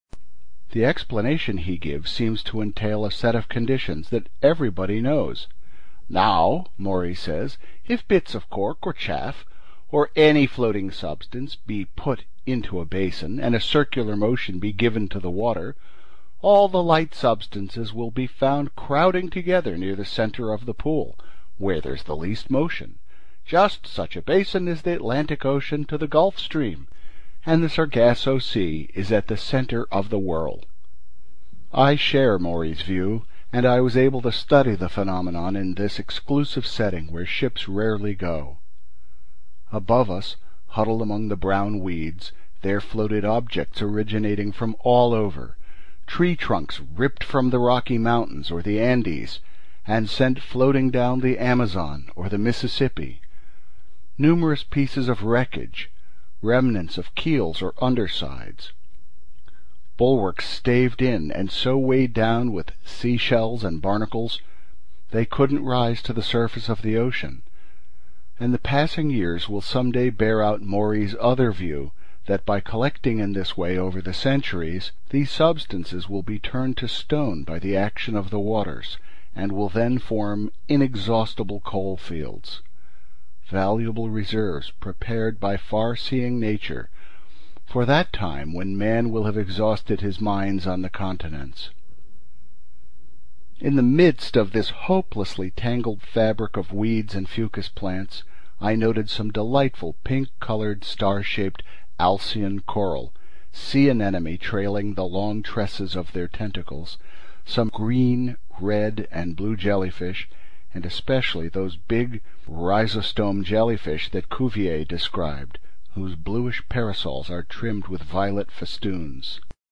英语听书《海底两万里》第405期 第25章 地中海四十八小时(37) 听力文件下载—在线英语听力室